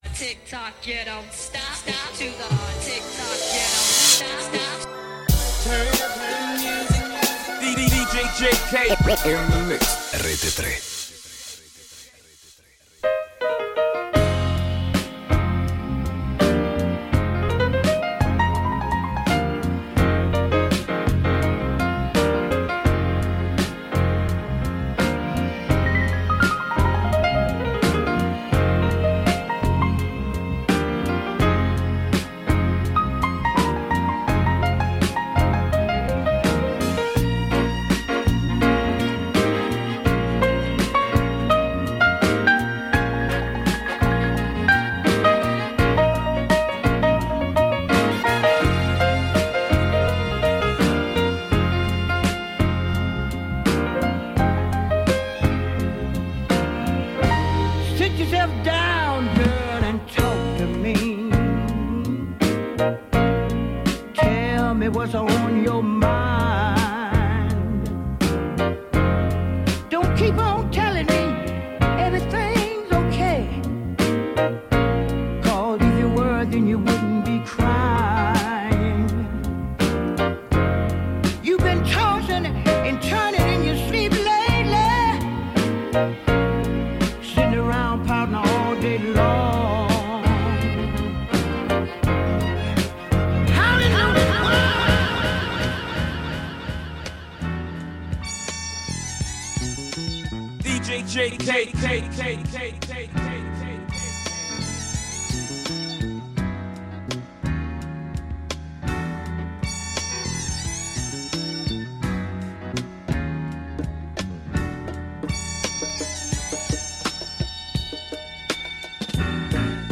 RAP / HIP-HOP